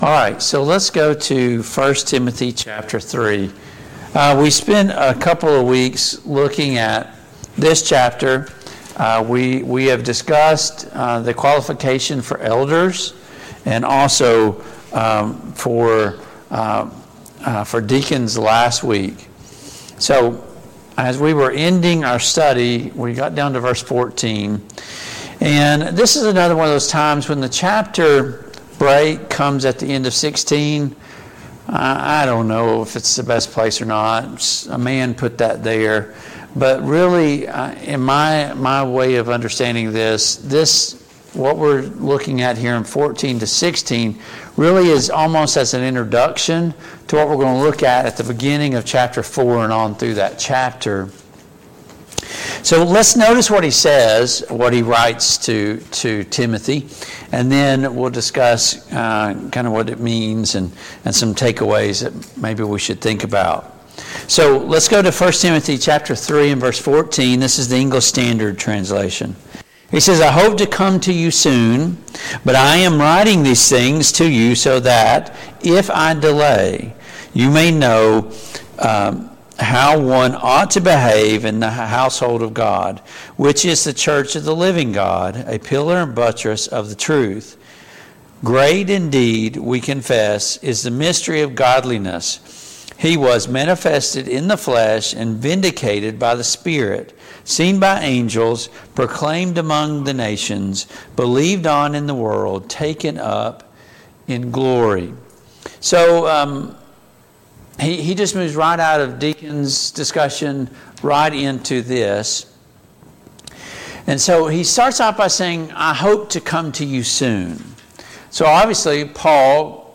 Passage: 1 Timothy 3:14-16, 1 Timothy 4:1-5 Service Type: Mid-Week Bible Study